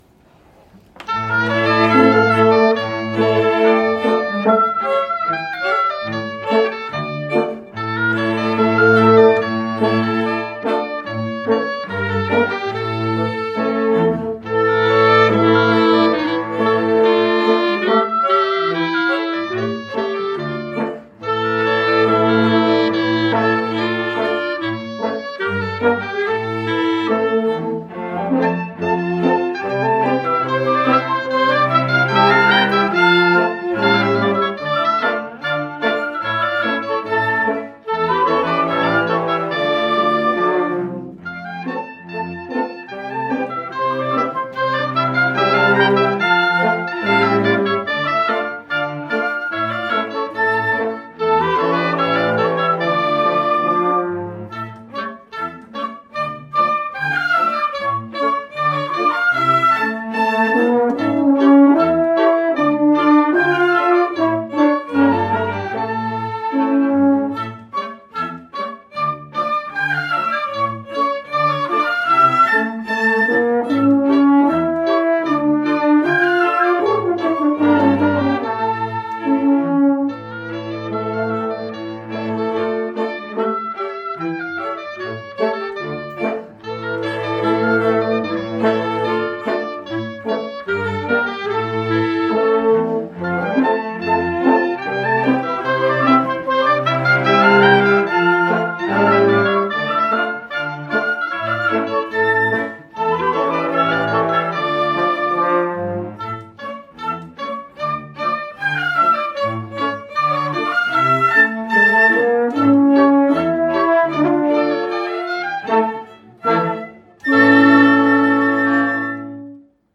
KLEZMER suite
Concert-22-mai-22-klezmer.mp3